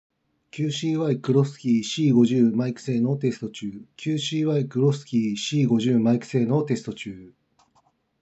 少しこもり気味
マイク性能は若干悪い
✅QCY Crossky C50マイク性能
平均よりちょっと下の作り。私の声自体が低いので悪く聞こえるだけかもしれないが、少しこもり気味になっています。